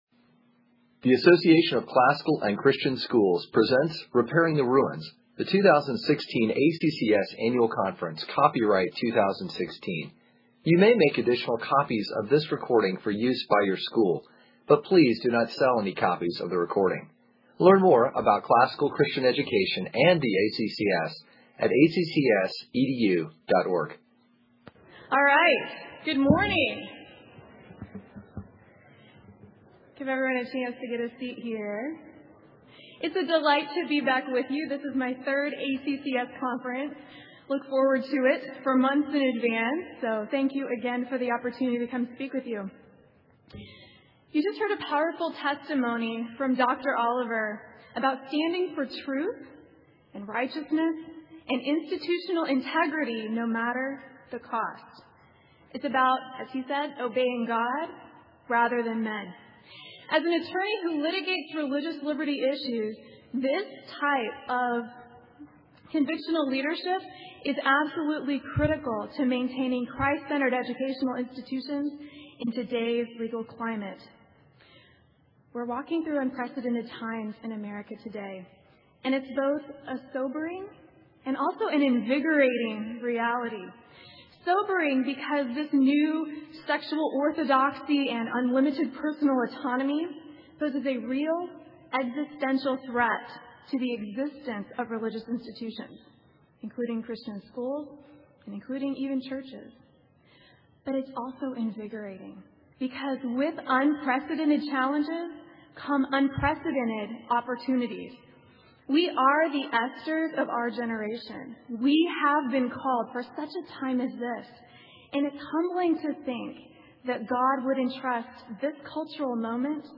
2016 Workshop Talk | 44:04:00 | All Grade Levels, Culture & Faith
Jan 10, 2019 | All Grade Levels, Conference Talks, Culture & Faith, Library, Media_Audio, Workshop Talk | 0 comments